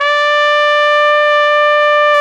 Index of /90_sSampleCDs/Roland LCDP12 Solo Brass/BRS_Tpt _ menu/BRS_Tp _ menu